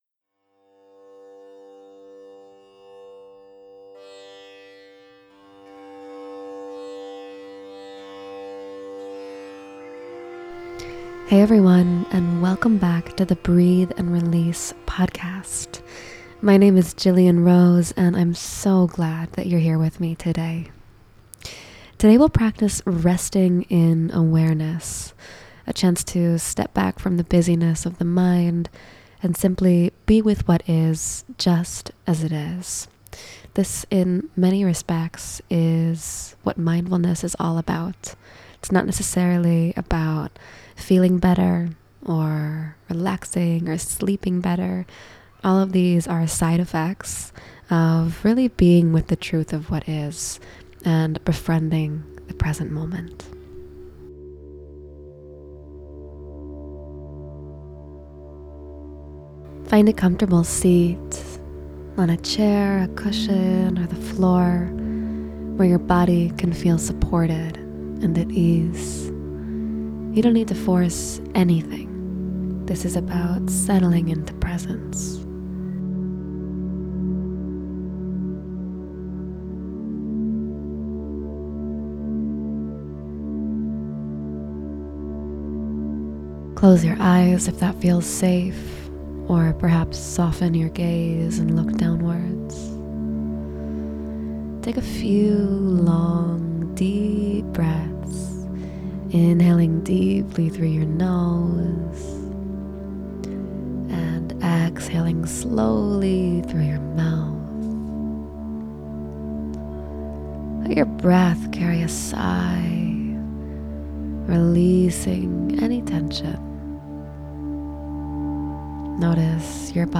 Resting Into Presence Meditation